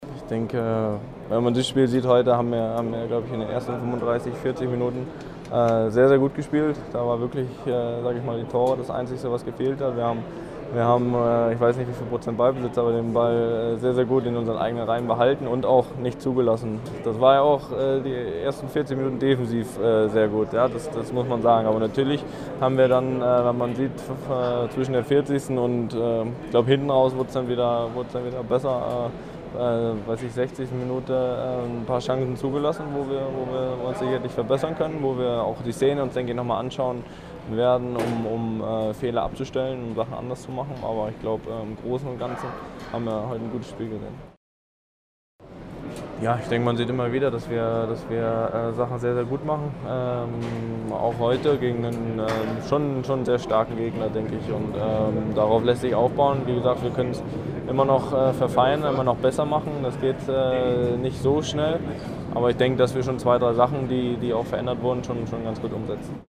Kollegengespräch und O-Töne: Zweiter Tag Audi Cup
O-Töne / Radiobeiträge, , , , , , , , , ,